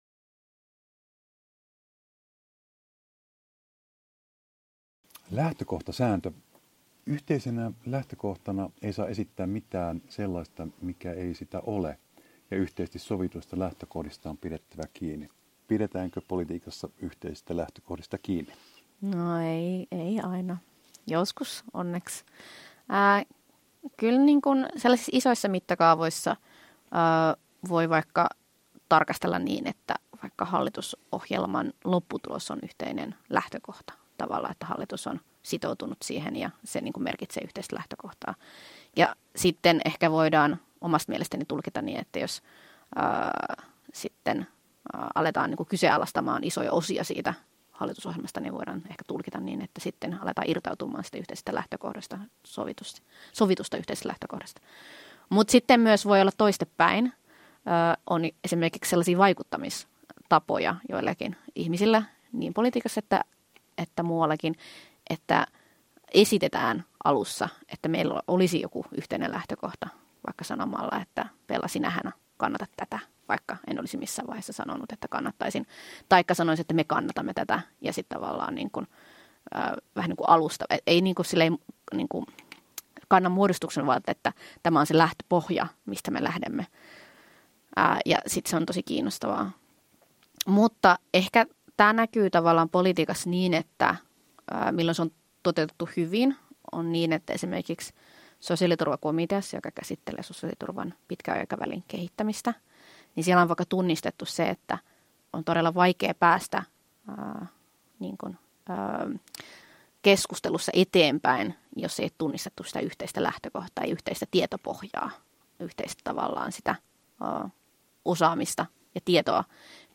Kansanedustajan haastattelu osa 3 paranneltu